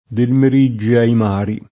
del mer&JJe ai m#ri] (Zanella) — solo dell’uso più ant. il latinismo meridie [mer&dLe]: infino a l’ultima stella che appare loro in meridie [inf&no a ll 2ltima St%lla ke app#re l1ro im mer&dLe] (Dante) — sim. il cogn.